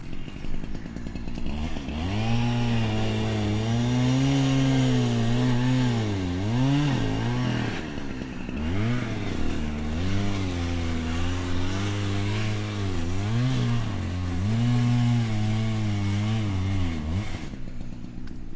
浴室抽油烟机
描述：浴室抽风机。立体声电容话筒
Tag: 现场录音